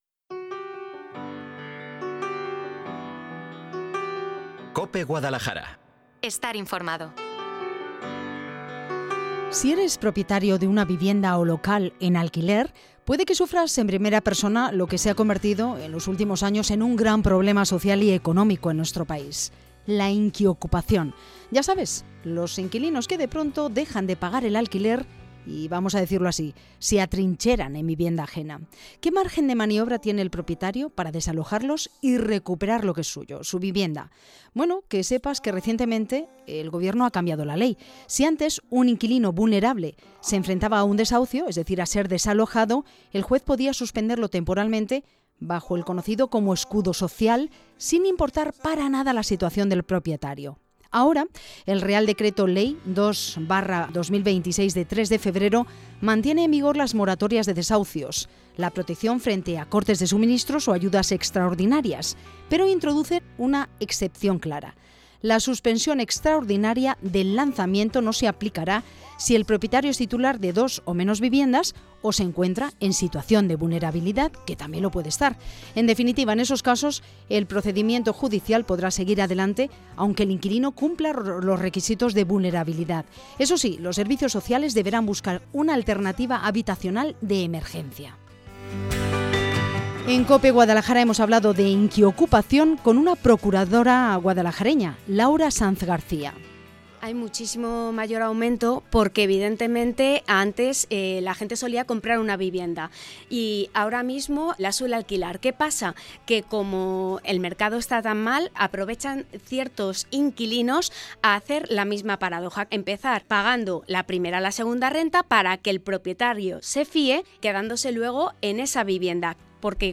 Hemos estado charlando en el programa de Cope Guadalajara sobre los deshacios y los problemas generados por la inquiocupación.